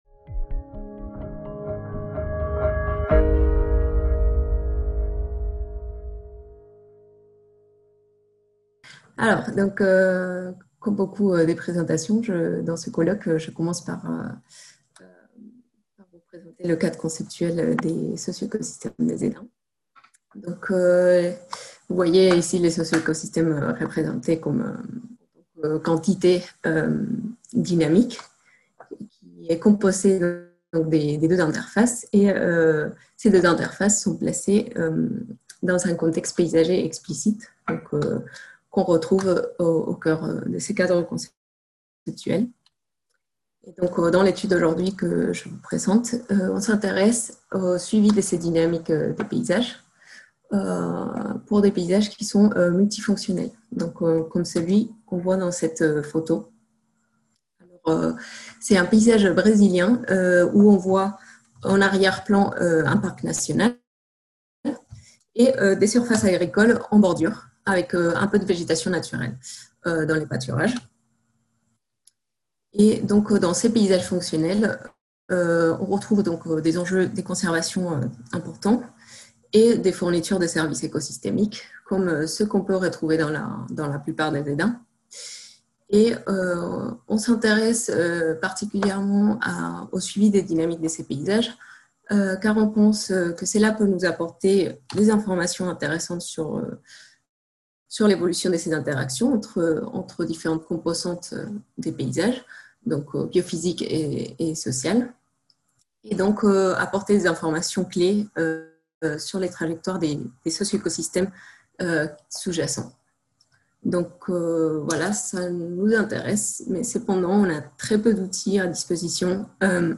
5e colloque des Zones Ateliers – CNRS 2000-2020